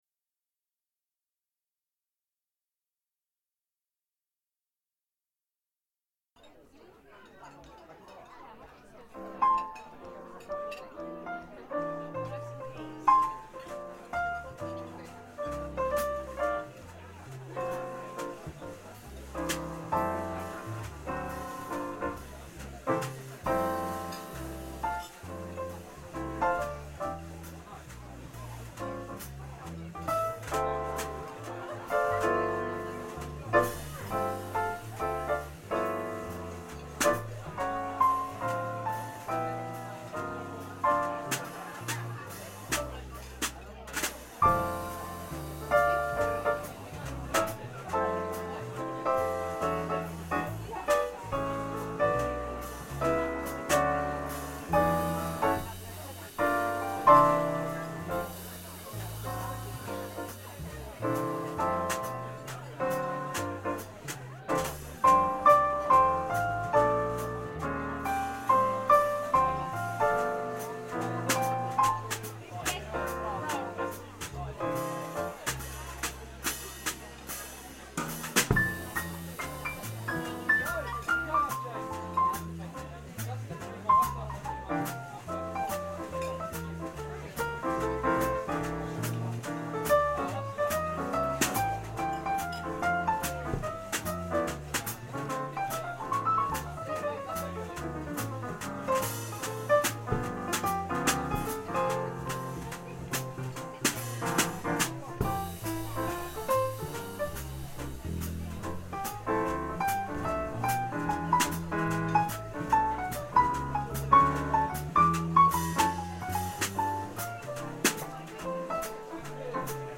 Trio - vocals, piano and bass